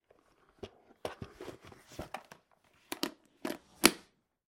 Звуки бинокля
Звук убранного в чехол бинокля